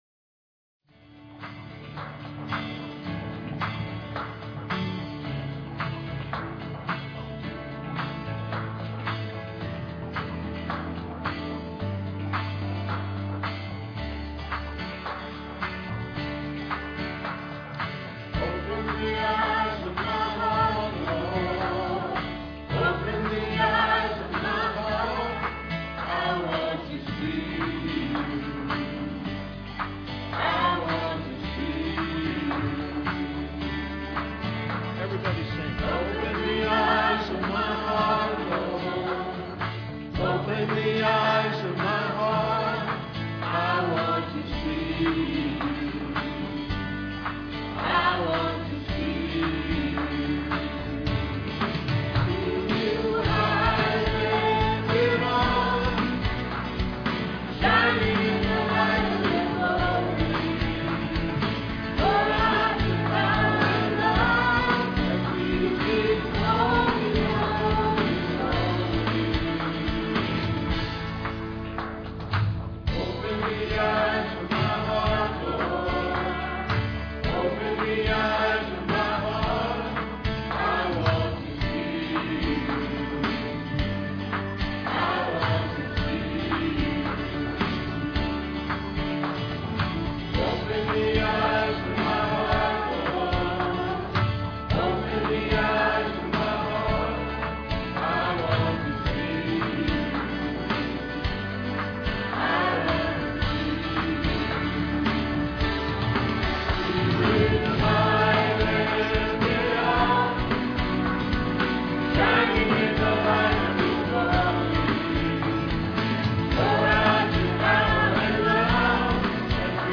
Piano offertory